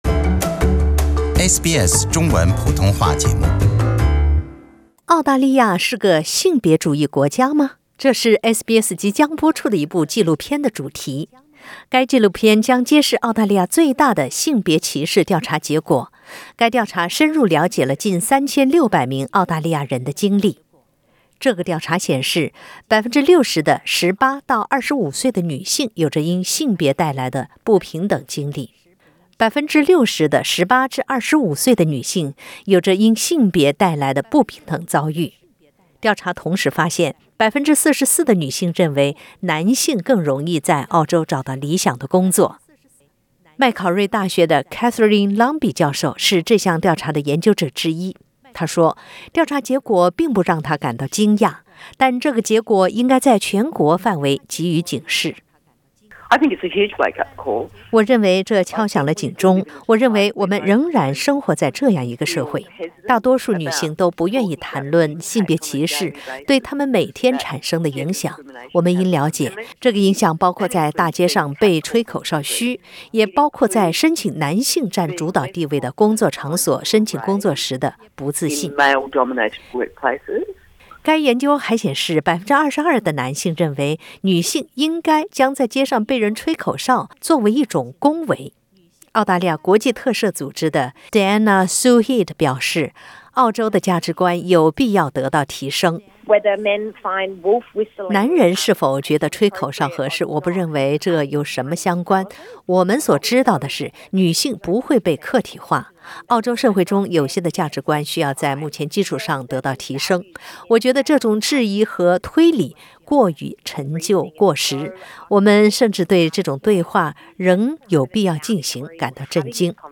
SBS Mandarin View Podcast Series